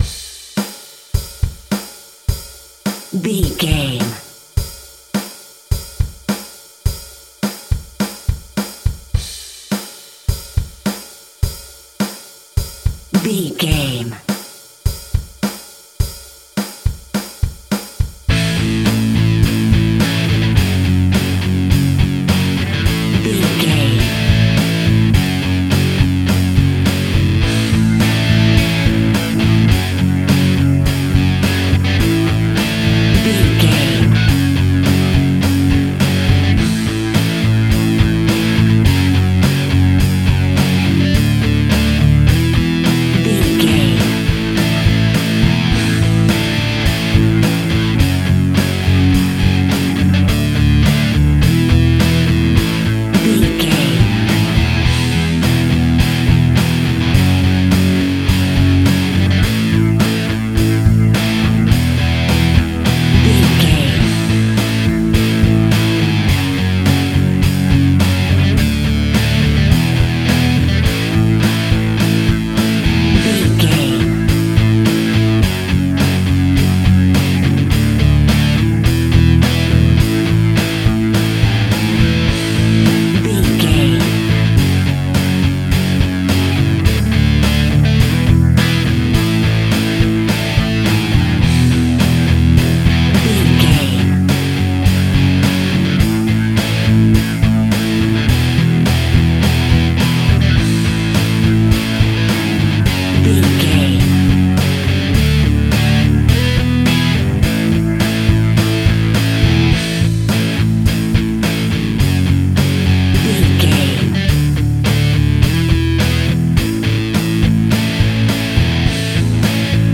Epic / Action
Fast paced
Ionian/Major
Fast
heavy metal
blues rock
distortion
Instrumental rock
drums
bass guitar
electric guitar
piano
hammond organ